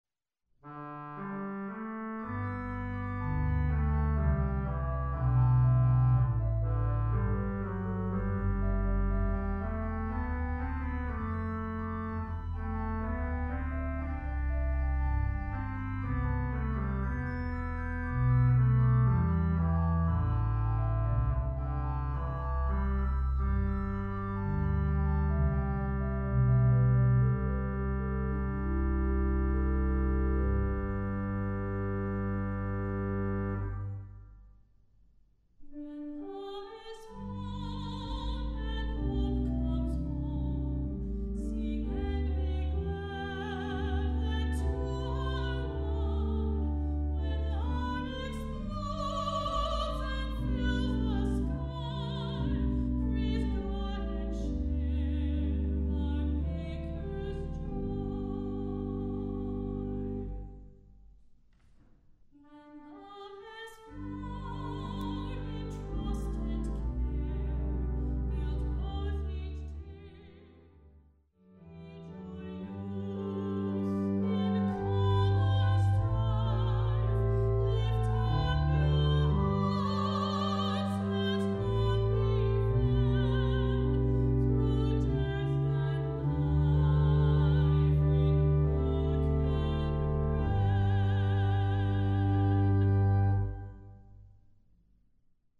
Voicing: Congregation